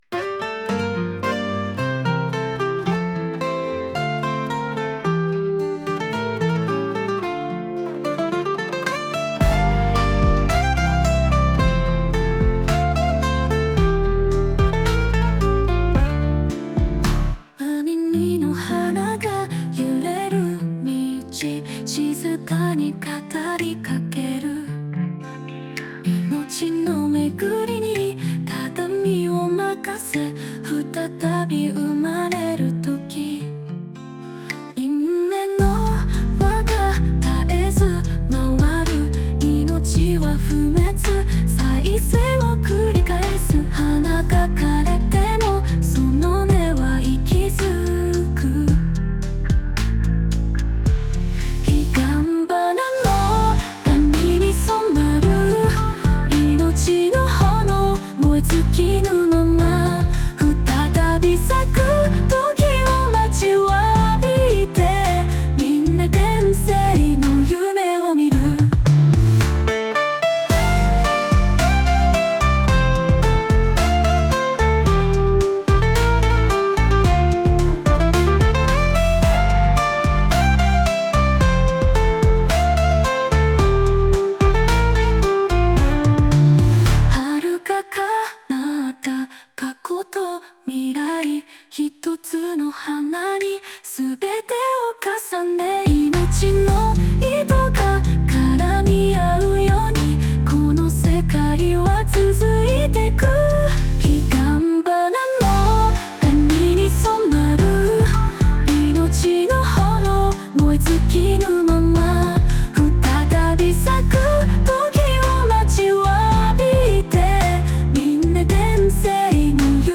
曲全般：SunoAI